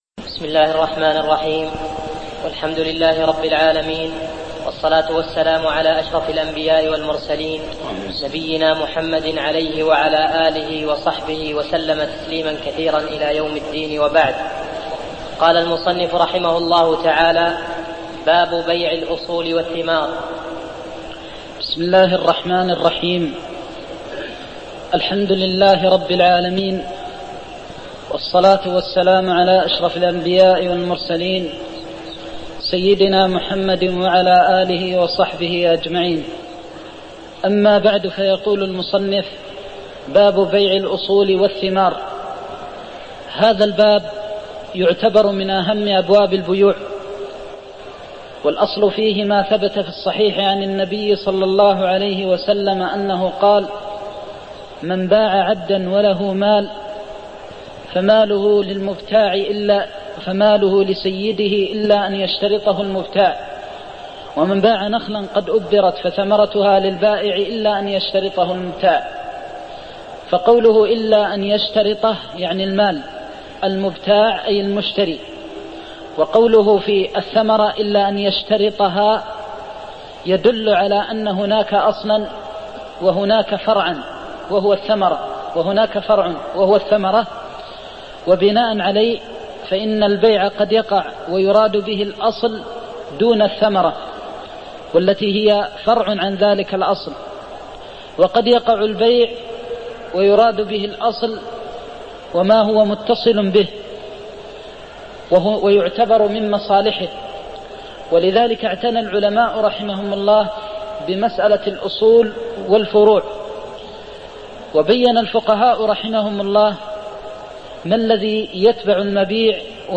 تاريخ النشر ٦ ربيع الأول ١٤١٧ هـ المكان: المسجد النبوي الشيخ